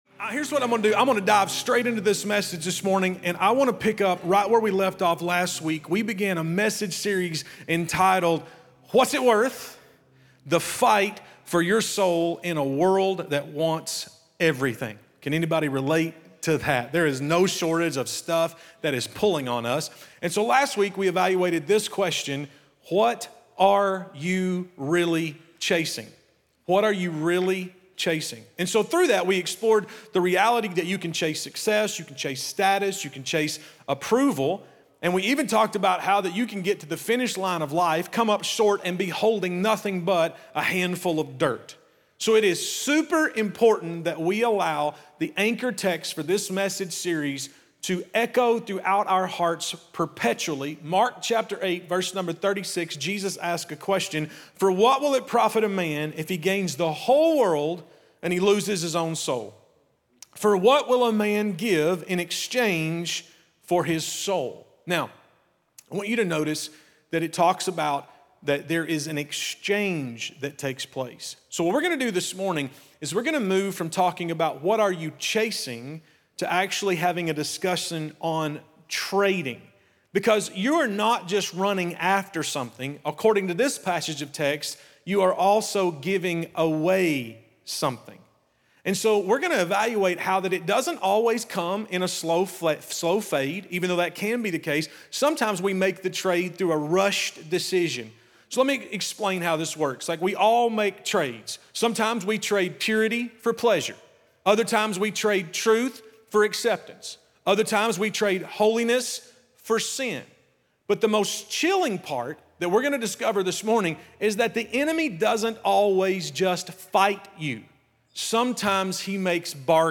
Discover how subtle trades and compromises can steal what’s most valuable—your soul—and how Jesus redeems every bad deal. From Mark 8:36 to the story of Jacob and Esau, this sermon challenges us to ask: What are you trading your soul for?